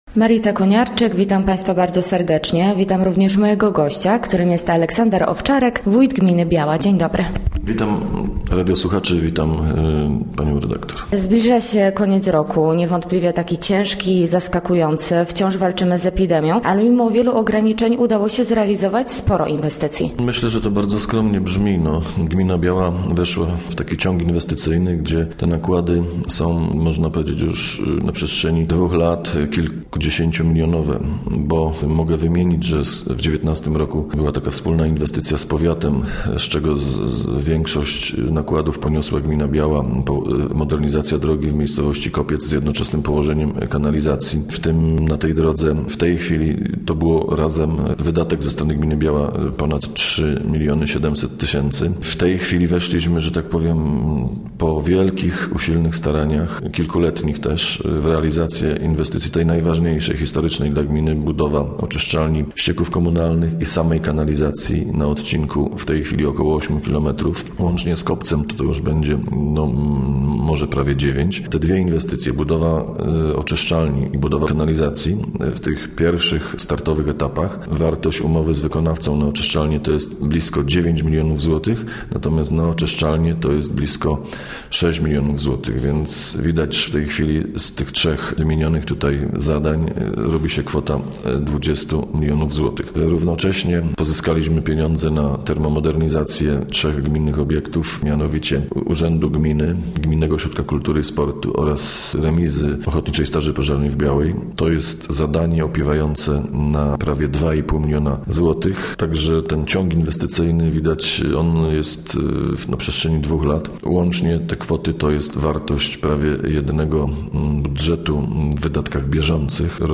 Gościem Radia ZW był Aleksander Owczarek, wójt gminy Biała